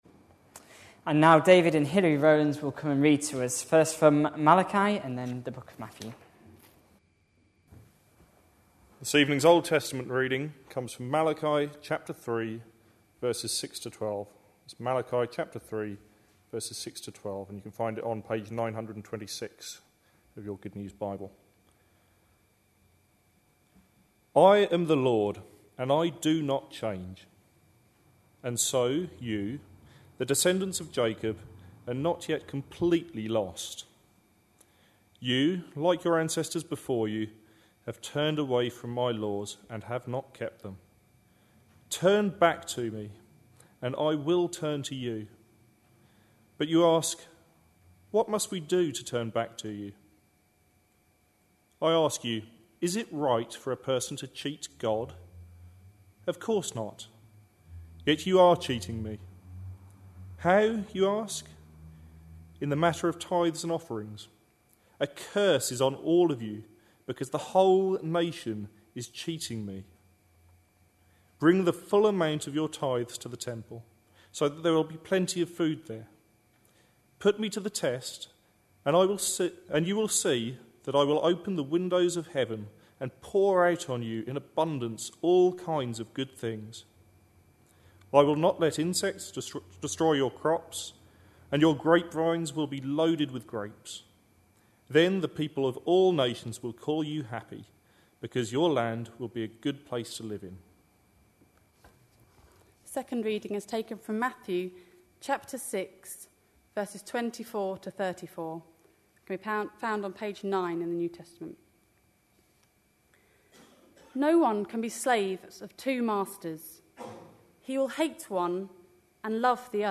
A sermon preached on 13th November, 2011, as part of our Malachi (Sunday evenings). series.